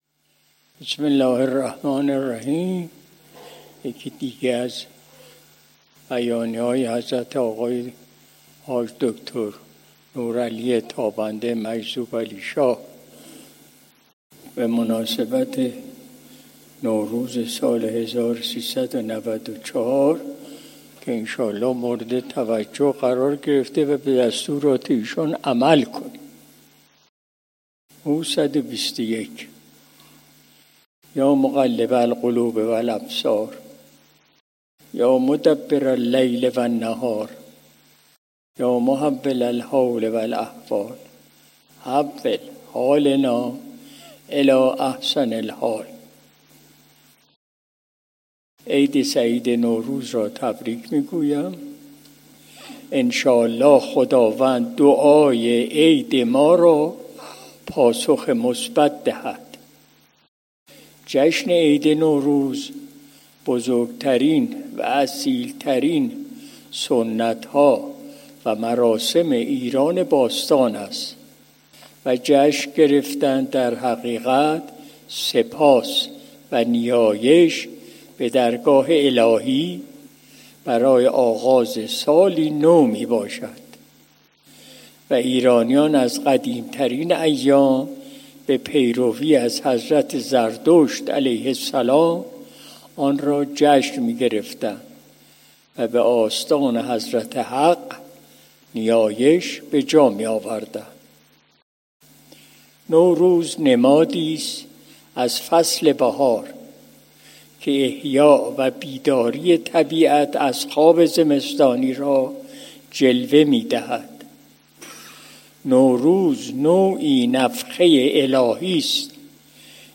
مجلس شب دوشنبه ۲۳ بهمن ماه ۱۴۰۱ شمسی